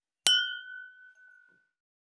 290シャンパングラス,ワイングラス乾杯,イタリアン,バル,フレンチ,夜景の見えるレストラン,チーン,カラン,キン,コーン,チリリン,カチン,チャリーン,
コップ